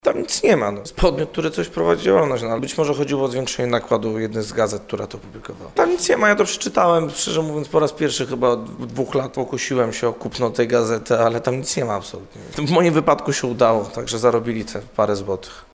– Tam nic nie ma, a cała publikacja miała na celu tylko podniesienie sprzedaży – powiedział w rozmowie z Radiem Warszawa radny Prawa i Sprawiedliwości Jacek Ozdoba.